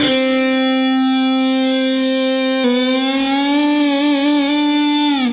Drone FX 01.wav